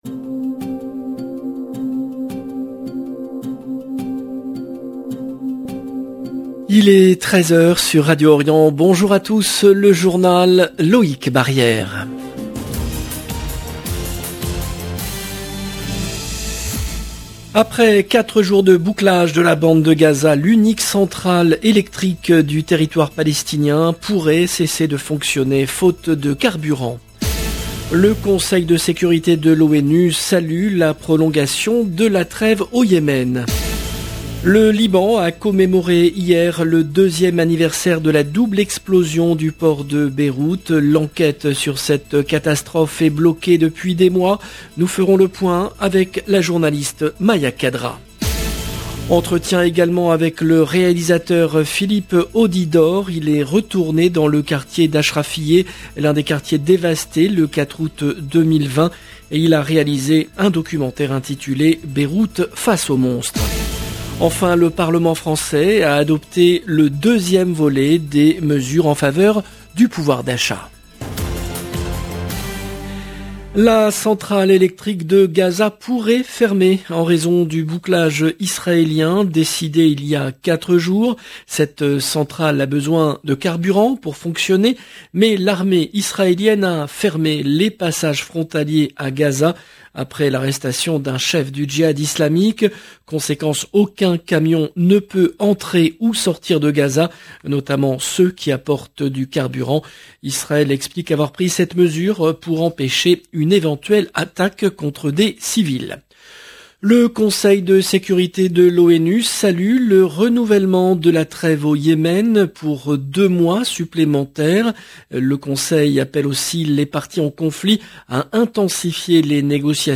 LE JOURNAL EN LANGUE FRANCAISE DE 13H DU 5/08/22